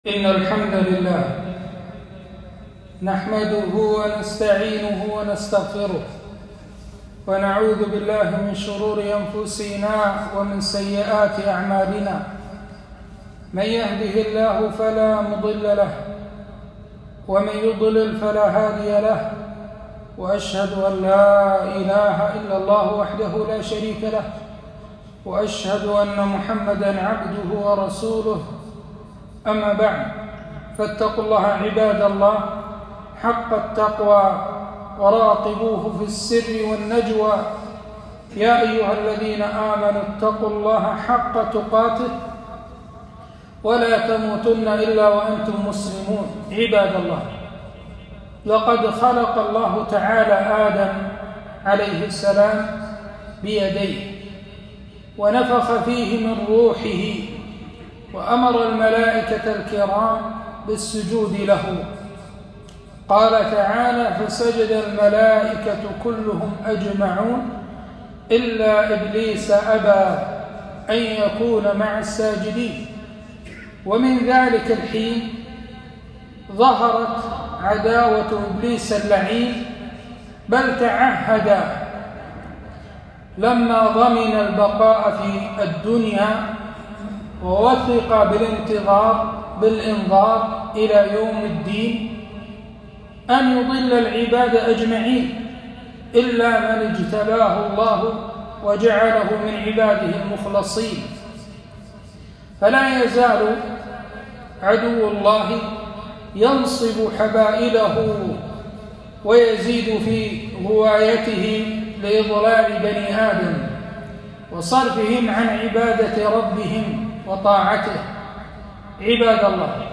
خطبة - عداوة الشيطان للإنسان